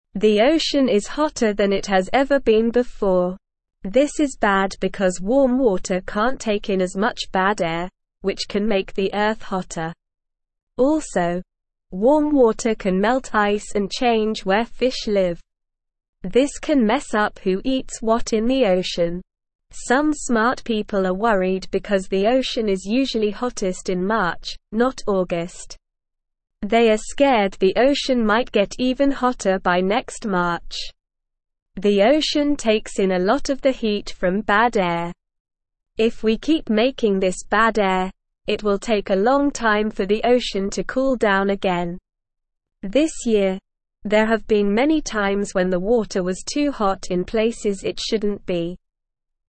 Slow
English-Newsroom-Beginner-SLOW-Reading-The-Ocean-is-Getting-Hotter-and-Thats-Bad.mp3